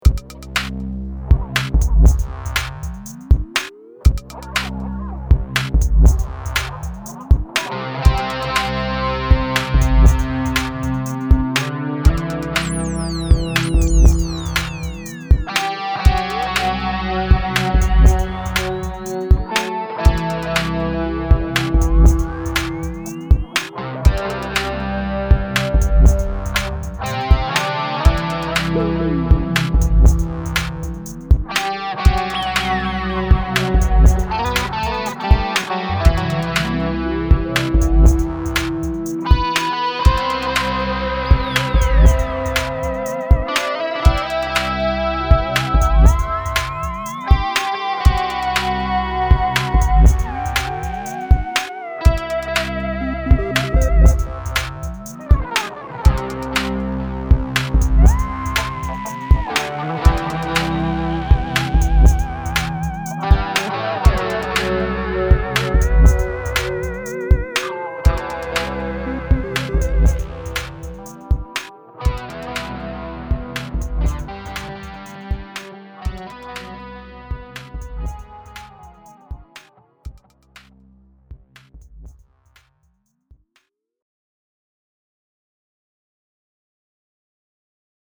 Aquí os dejo una prueba muy rápida grabada a mediodía antes de volver al trabajo: me encanta el estilo analógico de los sonidos…
kaossilator2.mp3